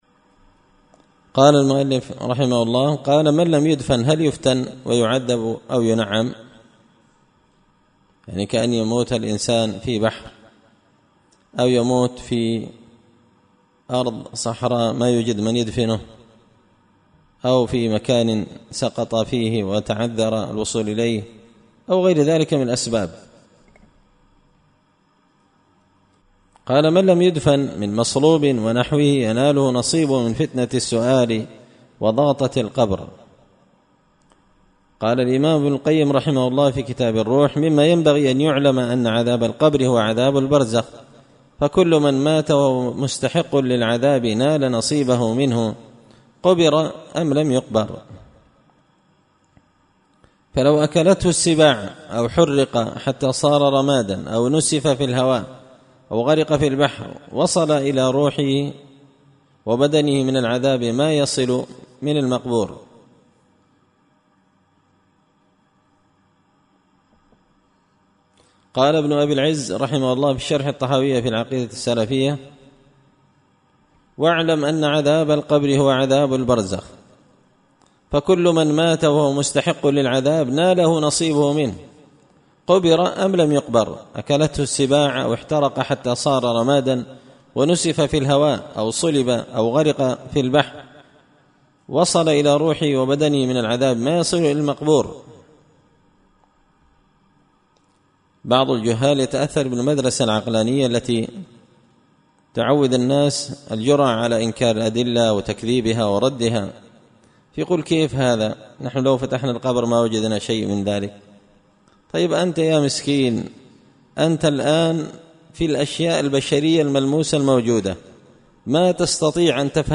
شرح لامية شيخ الإسلام ابن تيمية رحمه الله _الدرس 46